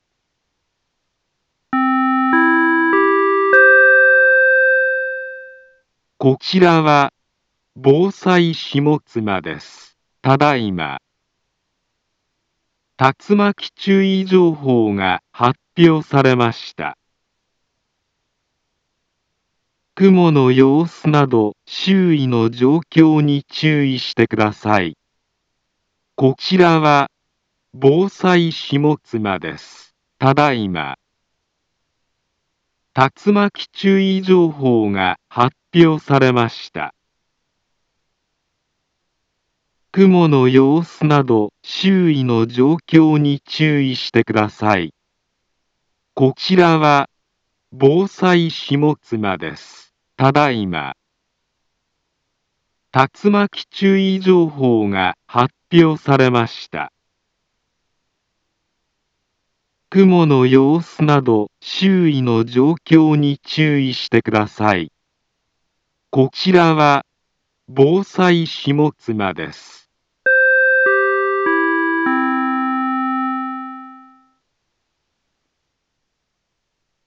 Back Home Ｊアラート情報 音声放送 再生 災害情報 カテゴリ：J-ALERT 登録日時：2022-07-03 14:49:44 インフォメーション：茨城県北部、南部は、竜巻などの激しい突風が発生しやすい気象状況になっています。